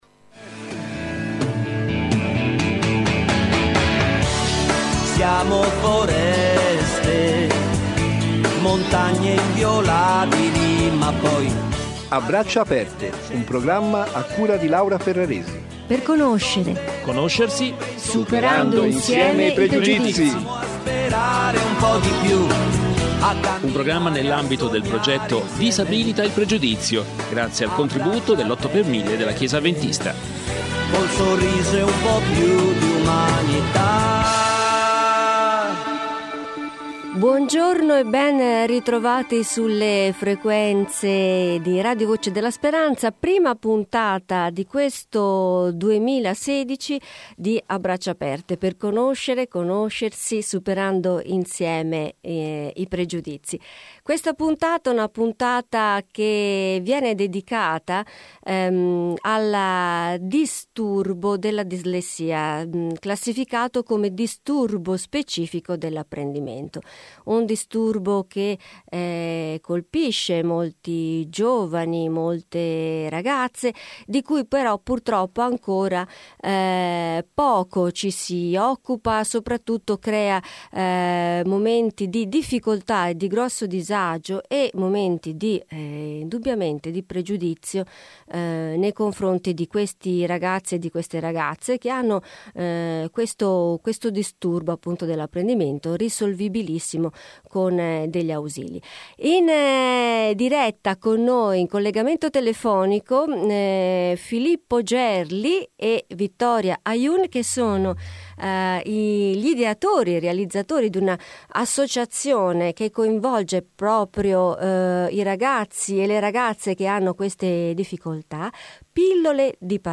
Durante questa nuova puntata di A braccia aperte affrontiamo insieme il tema del Disturbo Specifico dell’Apprendimento con due ospiti speciali in collegamento telefonico